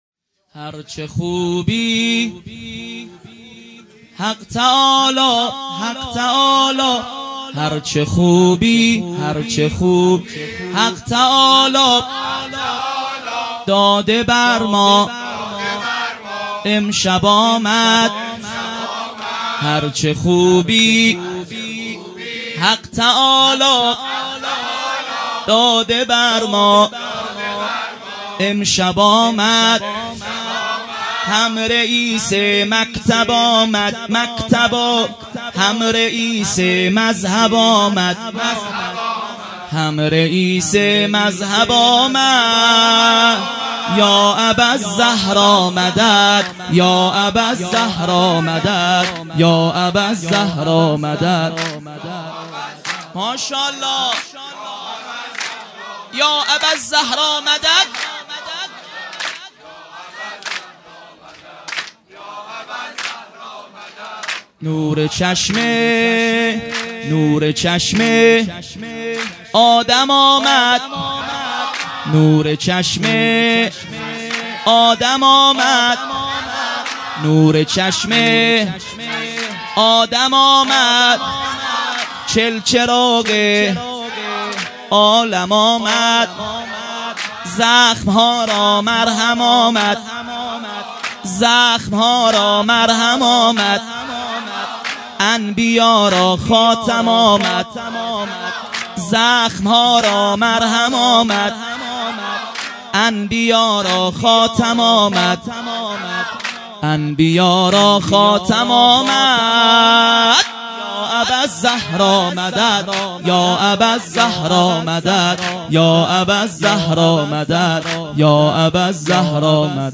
سرود ۲ | هر چه خوبی حق تعالی مداح